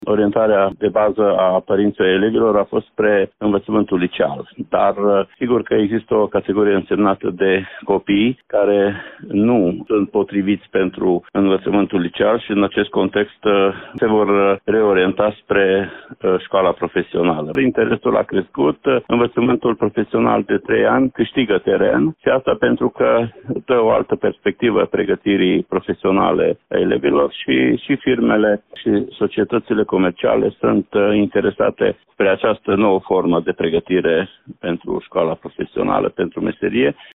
Inspectorul școlar general al județului Mureș, Ștefan Someșan.